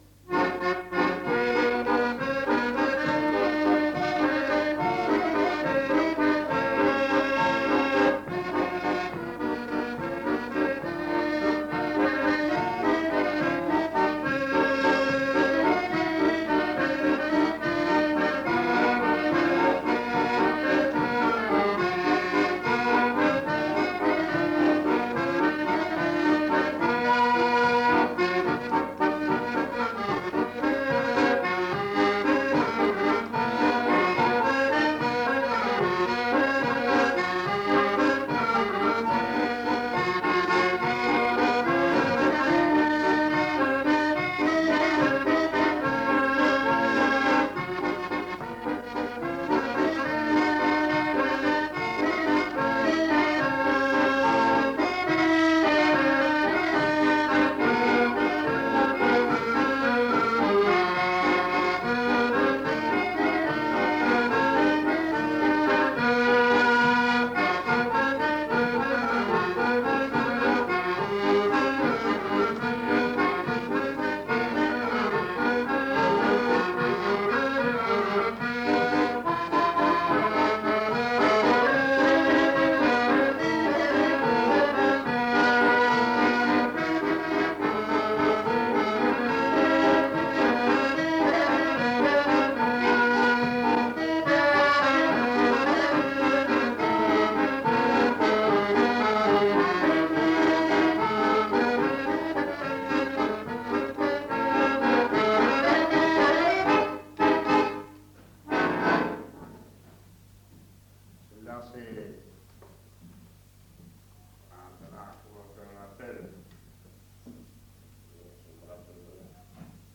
Aire culturelle : Viadène
Lieu : La Capelle (lieu-dit)
Genre : morceau instrumental
Instrument de musique : accordéon chromatique
Danse : valse